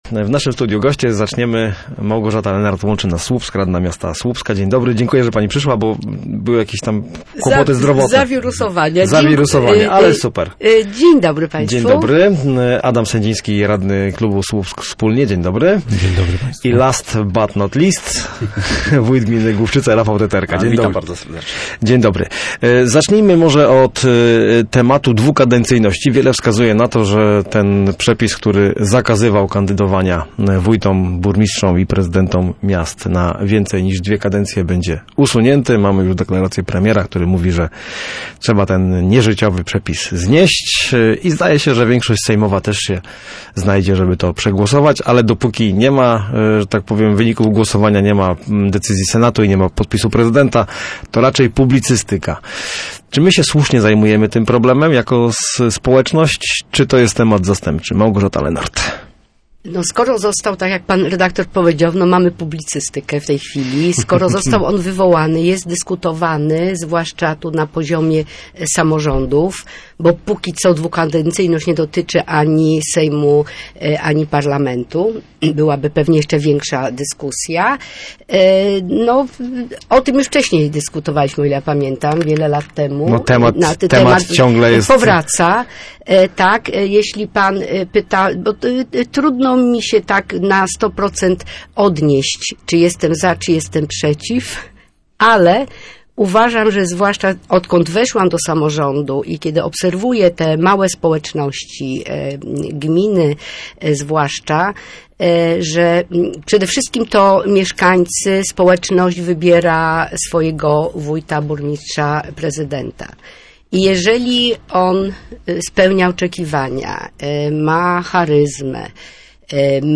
Dwukadencyjność w samorządzie nie jest potrzebna. Mieszkańcy sami wiedzą czy wójt, burmistrz, prezydent się sprawdzają – uważają goście programu „Gorący Temat” w Studiu Słupsk 102 FM w Radiu Gdańsk. Samorządowcy rozmawiali również o roli Słupska w strategii rozwoju Polski do 2035 roku.